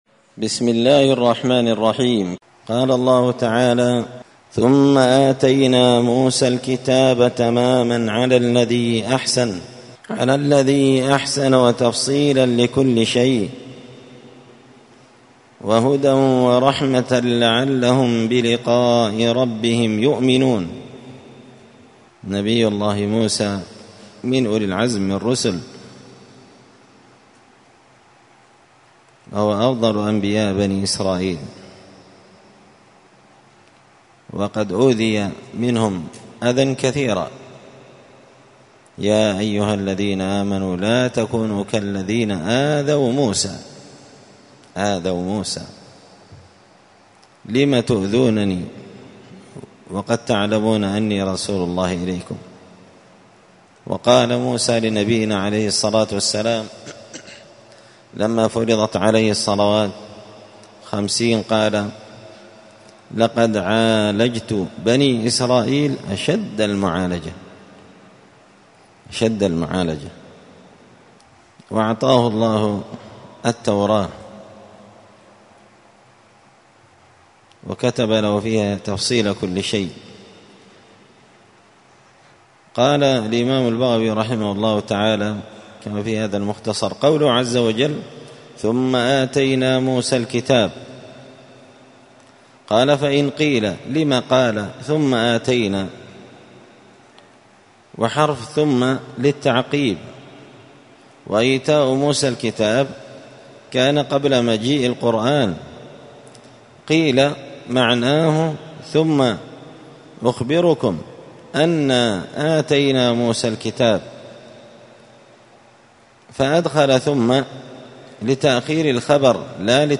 📌الدروس اليومية
مسجد الفرقان_قشن_المهرة_اليمن